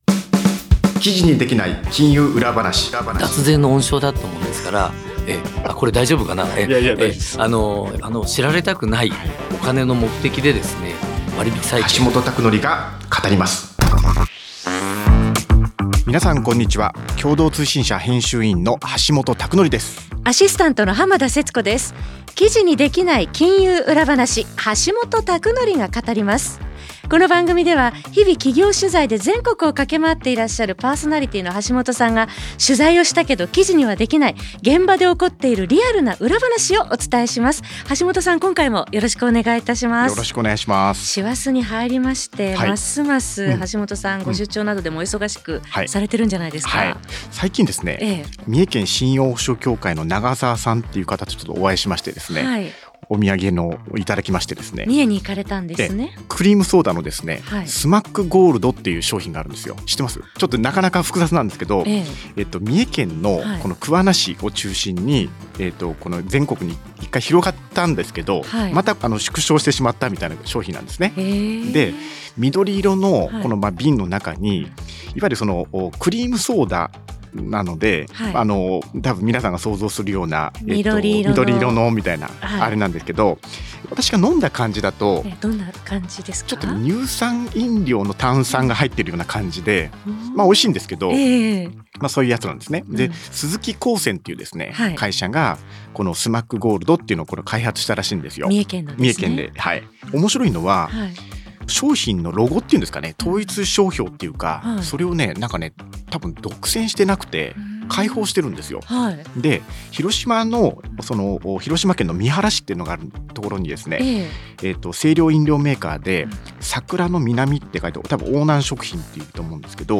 また、金融・行政・地方経済・経営など、ビジネス改革の最前線で活躍するプロフェッショナルをゲストに呼んで、ぶっちゃけトークを展開。